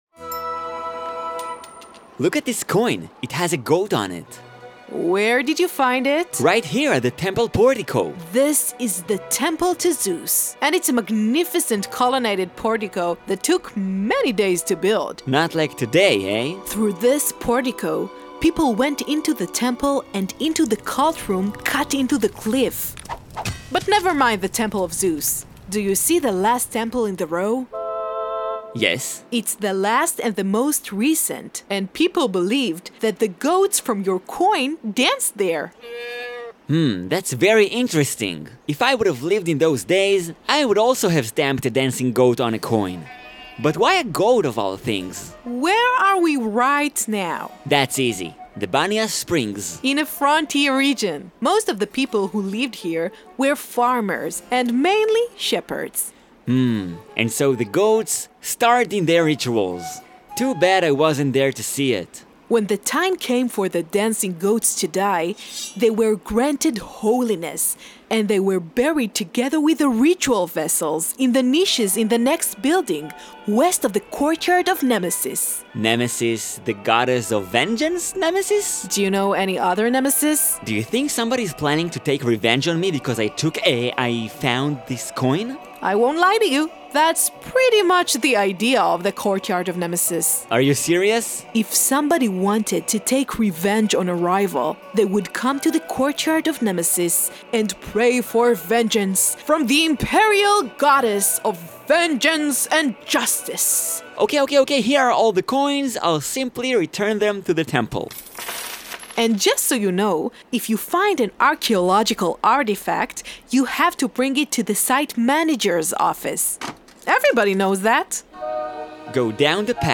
שמורת טבע בניאס BANIAS Trip talk